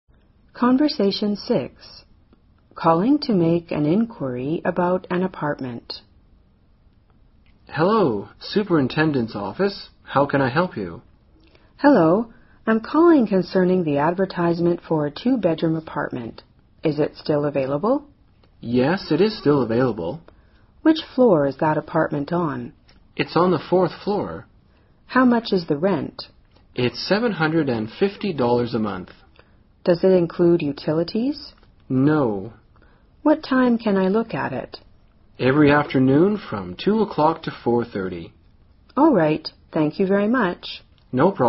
【对话6：打电话询问出租公寓的信息】